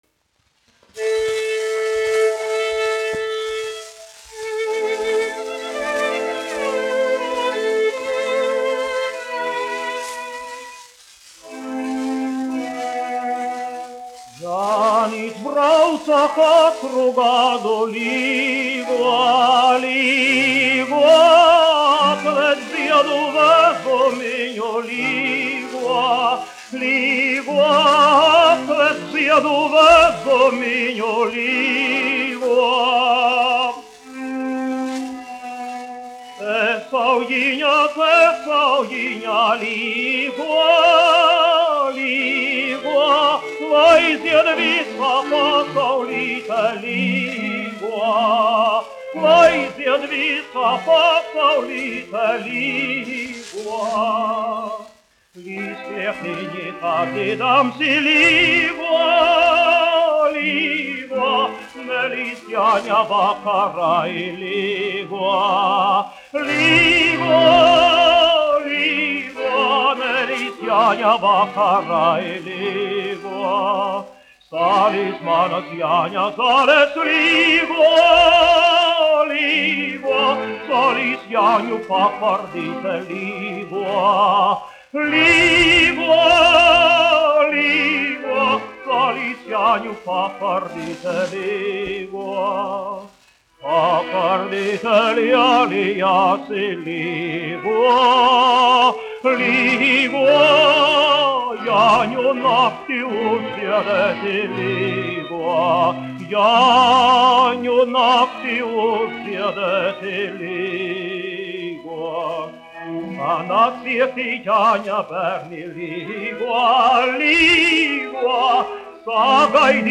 Mariss Vētra, 1901-1965, dziedātājs
1 skpl. : analogs, 78 apgr/min, mono ; 25 cm
Latviešu tautasdziesmas
Dziesmas (augsta balss) ar instrumentālu ansambli
Latvijas vēsturiskie šellaka skaņuplašu ieraksti (Kolekcija)